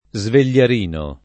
svegliarino [ @ vel’l’ar & no ] s. m.